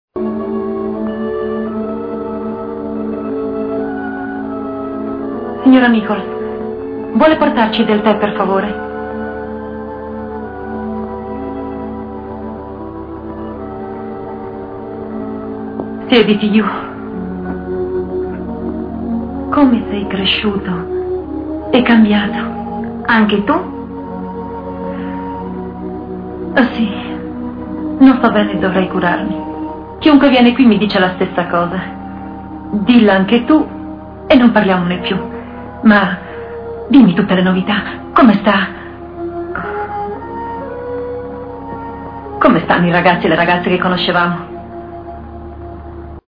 voce di Paolo Barbara nel film "Com'era verde la mia valle", in cui doppia Maureen O'Hara.